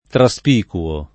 traspicuo [ tra S p & kuo ]